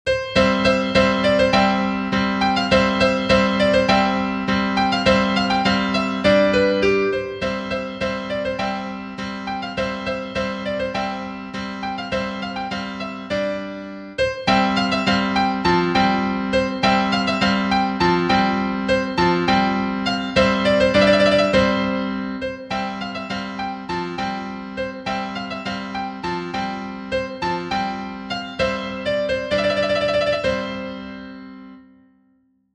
Vivaldi, A. Genere: Barocco "La Primavera" è il primo dei concerti solistici per violino noti come "Le quattro stagioni", composti da Antonio Vivaldi nel 1723. La Primavera - Incipit La Primavera - Incipit letto 412 volte